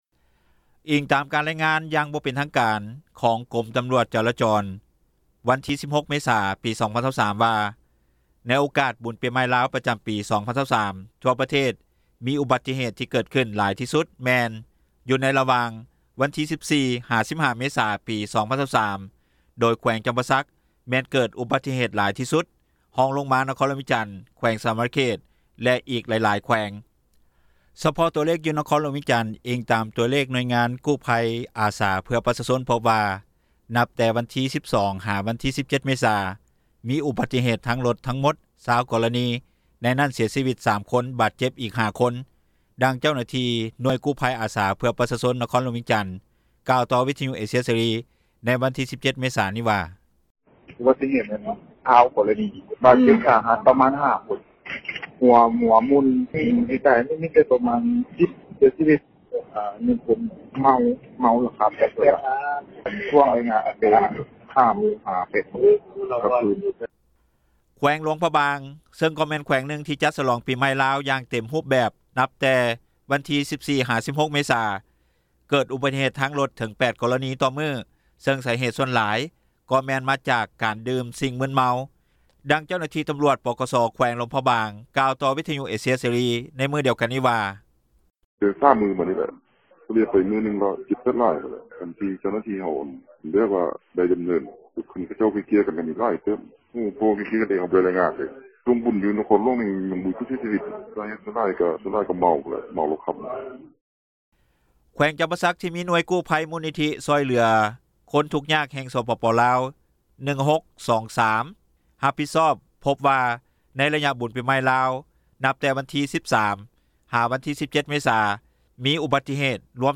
ດັ່ງເຈົ້າໜ້າທີ່ ໜ່ວຍກູ້ພັຍອາສາ ເພື່ອປະຊາຊົນ ນະຄອນຫຼວງຈັນ ກ່າວຕໍ່ວິທຍຸ ເອເຊັຽ ເສຣີ ໃນວັນທີ 17 ເມສາ ນີ້ວ່າ:
ດັ່ງເຈົ້າໜ້າທີ່ຕຳຣວດ ປກສ ແຂວງຫຼວງພຣະບາງ ກ່າວຕໍ່ວິທຍຸເອເຊັຽເສຣີ ໃນມື້ດຽວກັນນີ້ວ່າ: